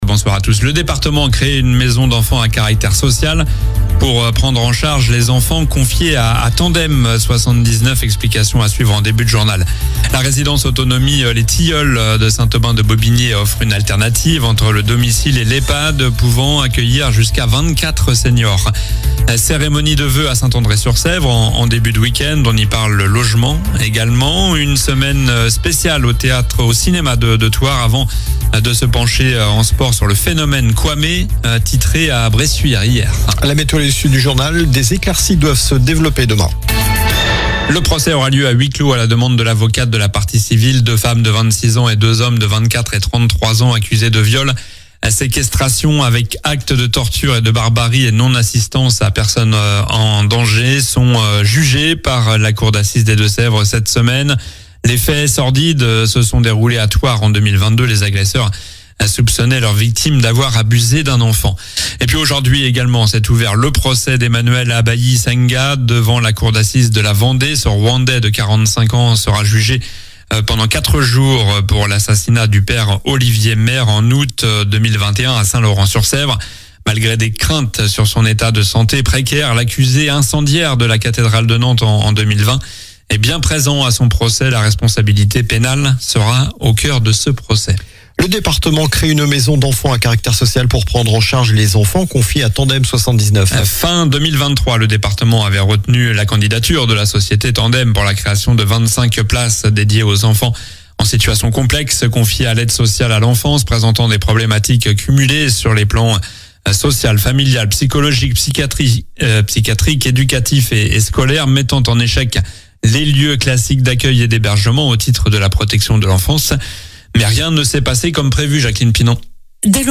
Journal du lundi 19 janvier (soir)